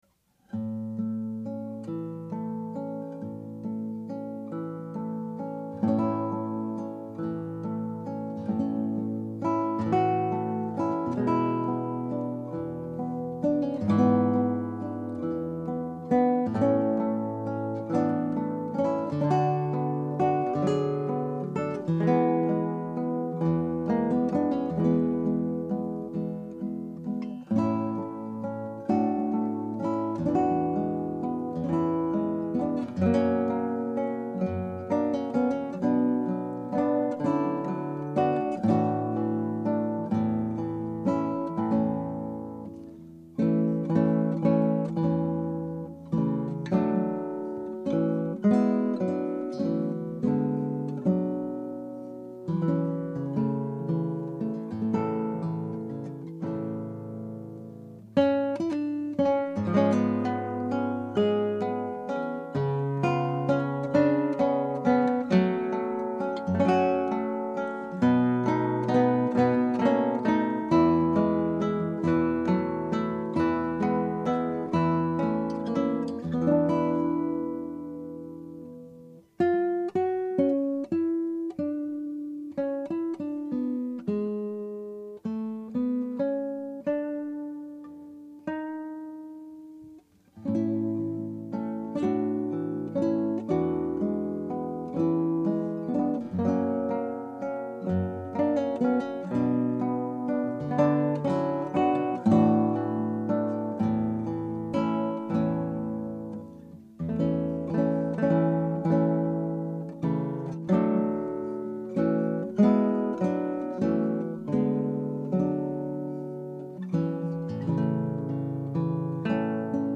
Scraps from the Operas arranged for Two Guitars
Scrap 1: Andante Sostenuto.
Scrap 2 (2:12): Allegro.